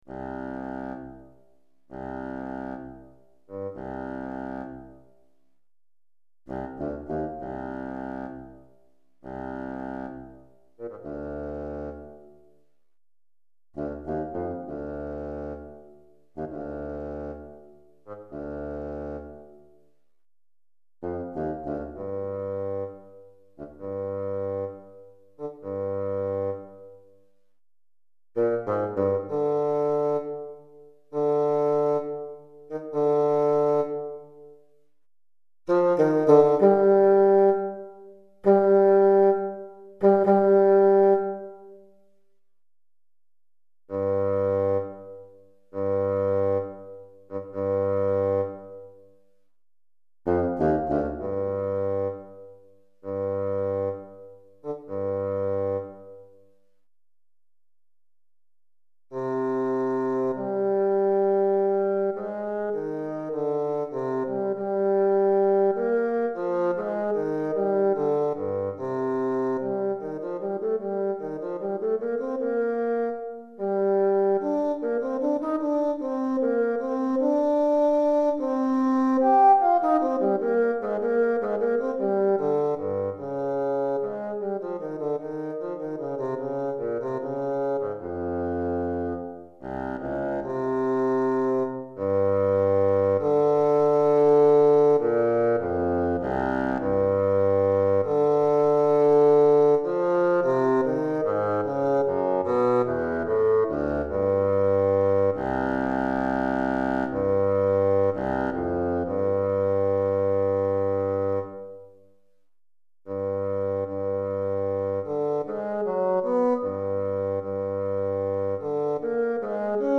Basson Solo